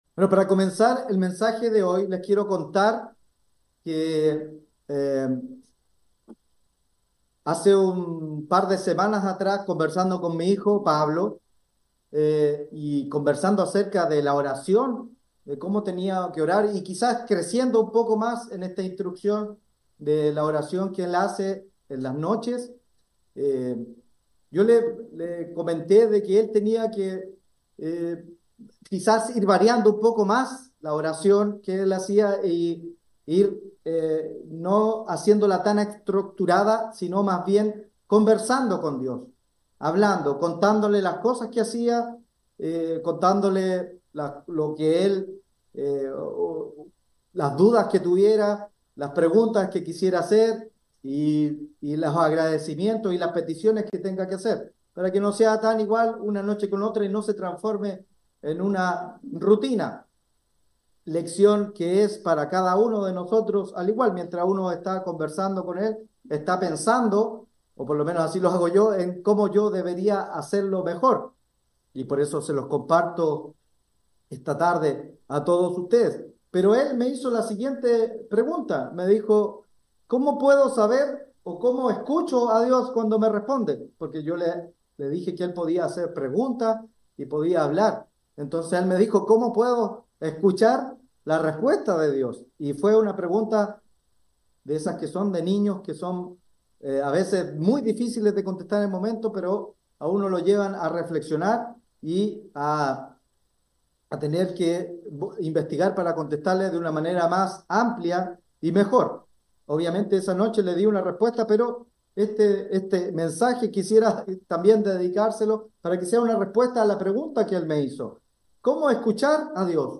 Cuando oramos le comunicamos a Dios nuestros agradecimientos, necesidades e inquietudes. Sin embargo uno puede llegar a preguntarse... ¿cómo recibimos la respuesta de parte de Dios? Mensaje entregado el 9 de diciembre de 2023.